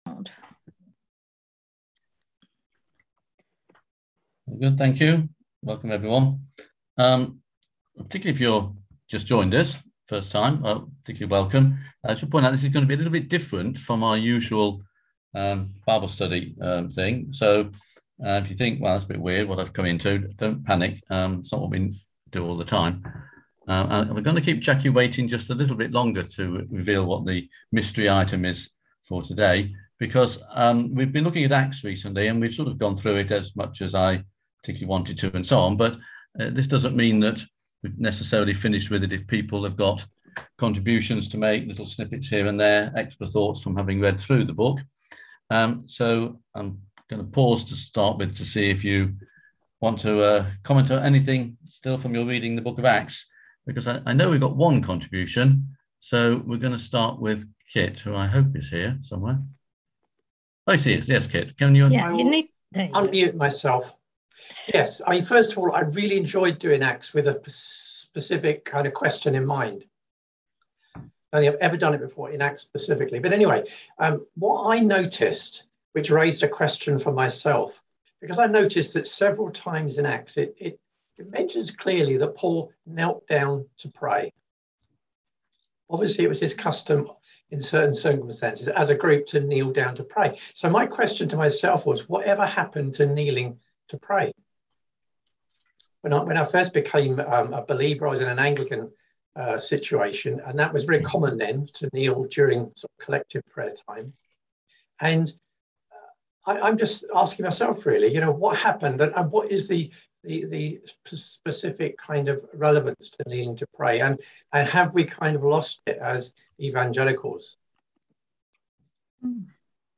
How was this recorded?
On October 28th at 7pm – 8:30pm on ZOOM